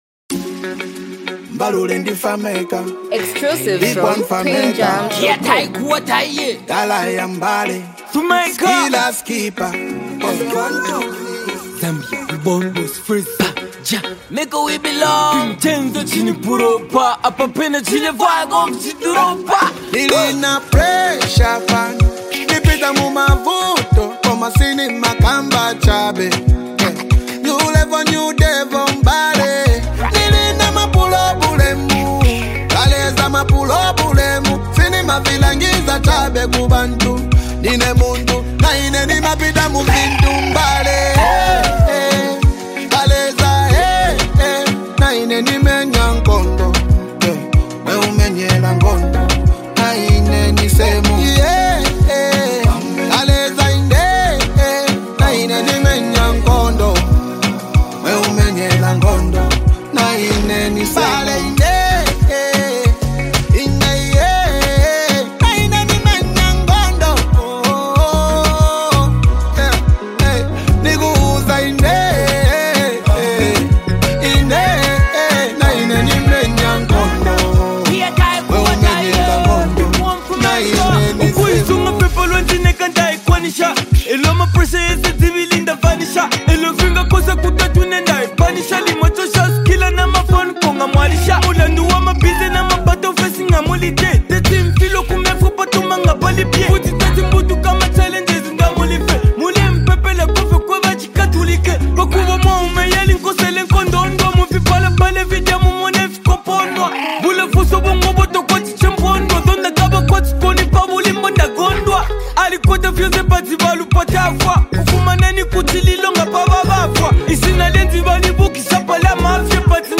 hype, street vibes, and a powerful hook with unique rap flow